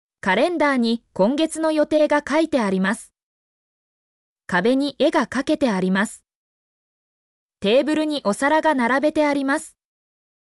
mp3-output-ttsfreedotcom-2_ZvLcYfly.mp3